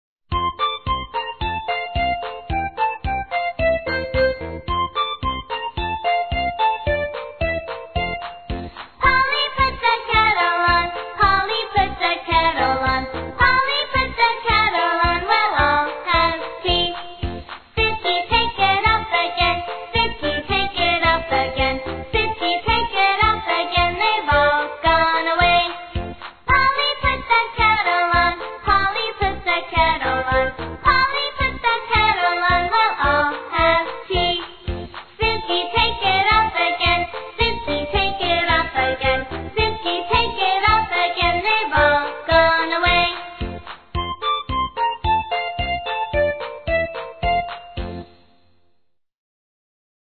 在线英语听力室英语儿歌274首 第167期:Polly,Put the Kettle On的听力文件下载,收录了274首发音地道纯正，音乐节奏活泼动人的英文儿歌，从小培养对英语的爱好，为以后萌娃学习更多的英语知识，打下坚实的基础。